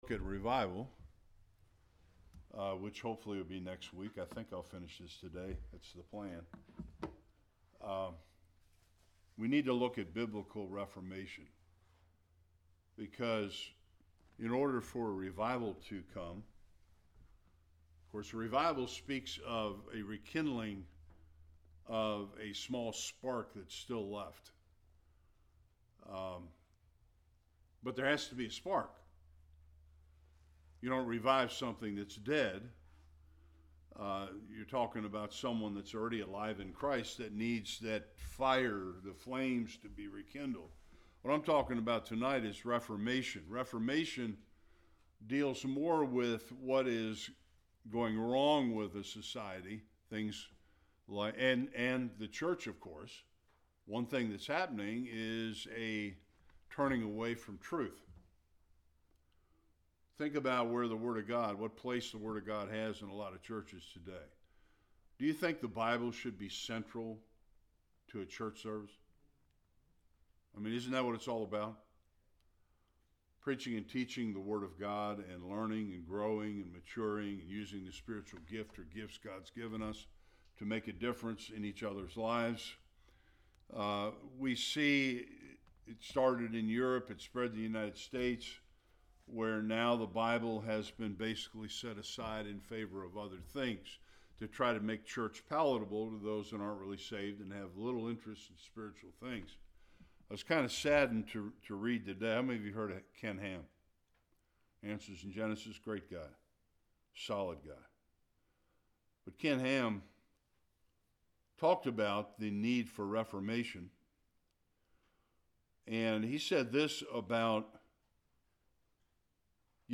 Various Passages Service Type: Bible Study We definitely need a sweeping revival in our churches